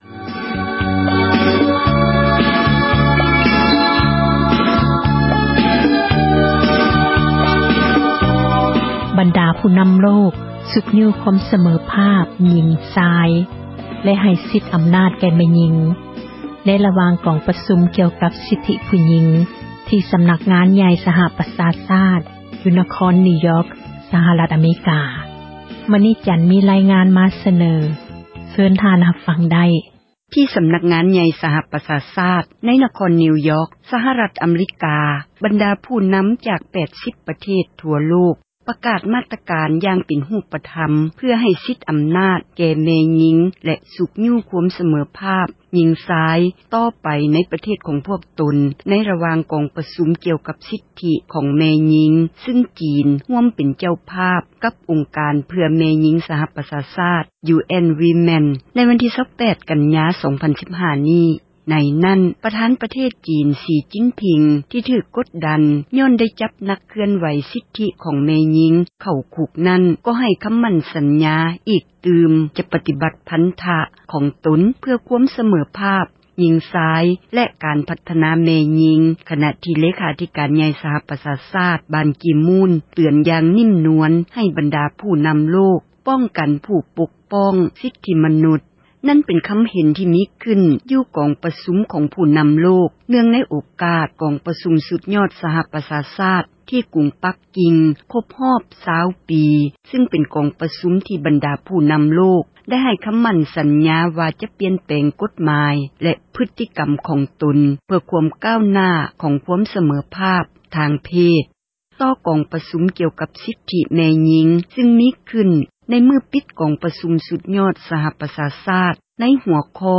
ມີ ຣາຍງານ ມາສເນີ ເຊີນທ່ານ ຮັບຟັງໄດ້.